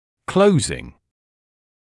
[‘kləuzɪŋ][‘клоузин]закрывание, закрытие; инговая форма от to close